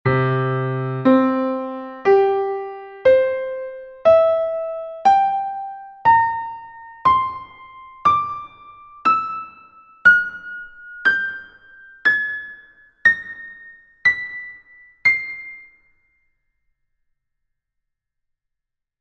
Sequência de acordes iniciada pela tríade maior (C-E-G) a esquerda, seguida pela tríade menor (E-G-B) e terminada tríade menor (A-C-E) a direita
harmonic-serie.mp3